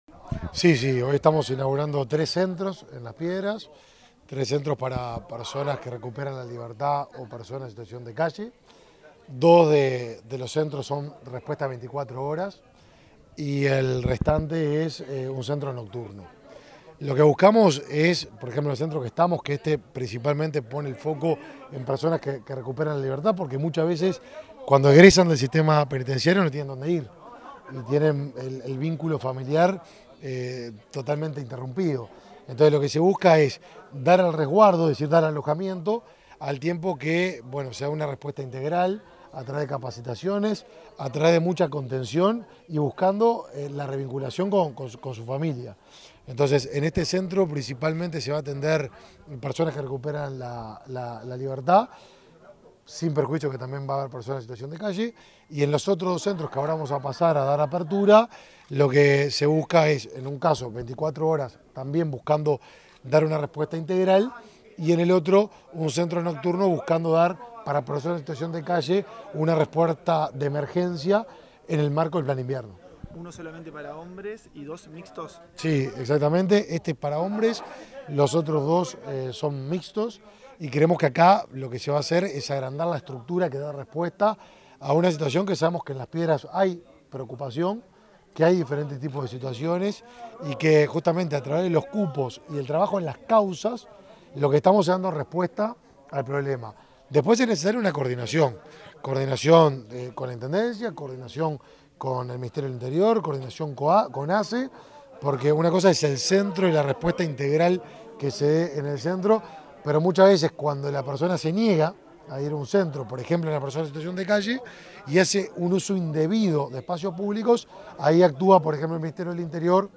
Declaraciones del ministro de Desarrollo Social, Martín Lema
Declaraciones del ministro de Desarrollo Social, Martín Lema 19/05/2023 Compartir Facebook X Copiar enlace WhatsApp LinkedIn Tras la inauguración de un centro de alojamiento colectivo en Las Piedras, orientado a la reinserción social de hombres que se encuentran en situación de calle, este 19 de mayo, el ministro de Desarrollo Social, Martín Lema, efectuó declaraciones.